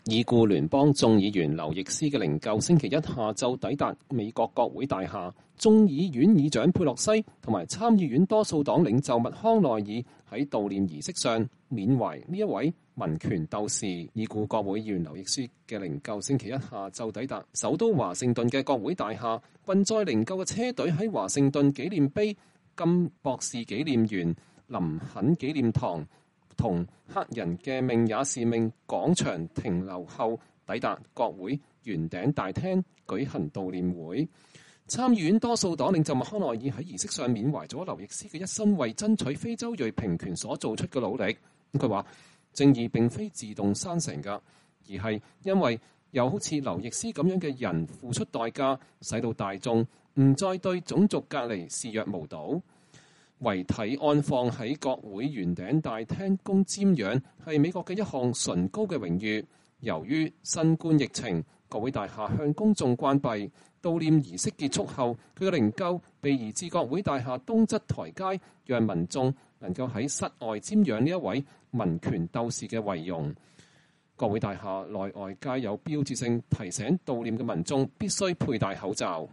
眾議員議長佩洛西和參議院多數黨領袖麥康奈爾在悼念儀式上緬懷這位民權鬥士。